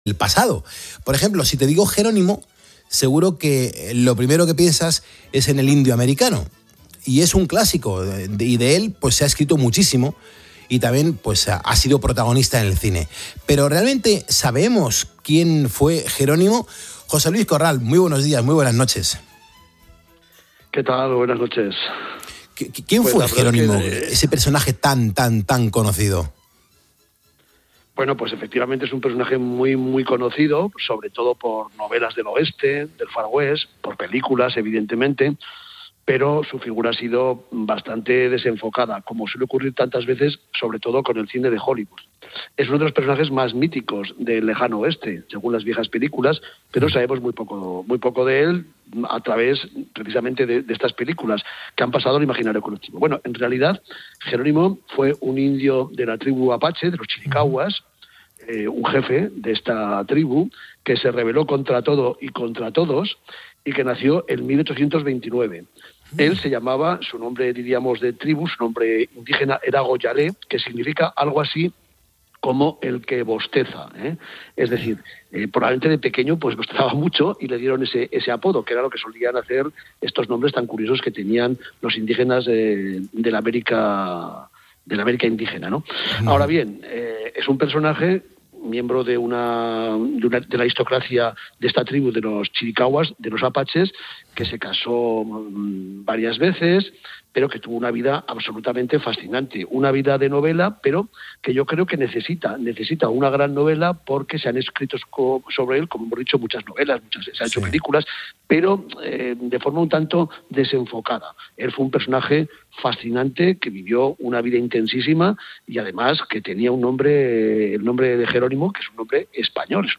Carlos Moreno 'El Pulpo' conoce la historia del indio Gerónimo con el historiador José Luis Corral